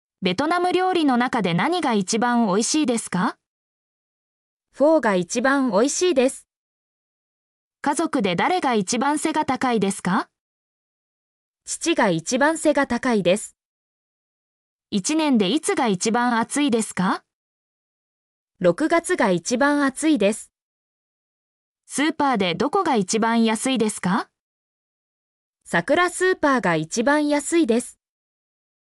mp3-output-ttsfreedotcom-90_WKaOTTde.mp3